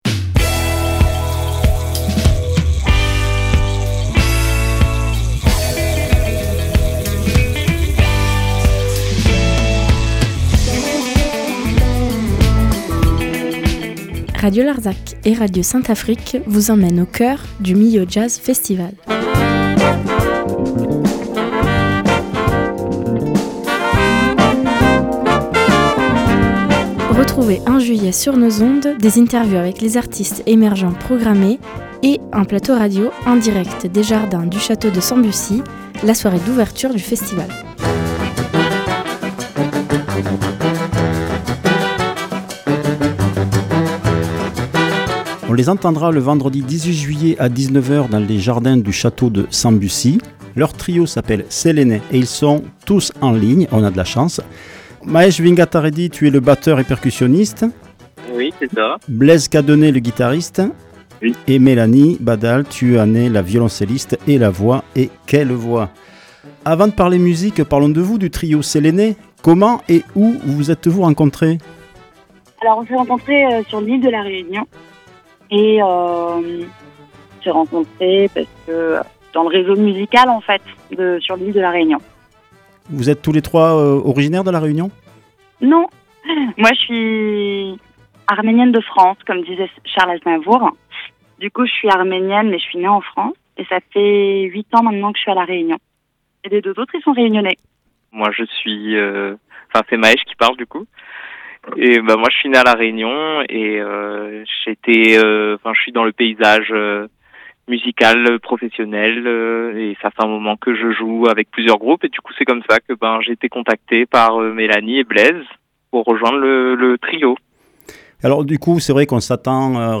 Millau Jazz Festival 2025 – Interview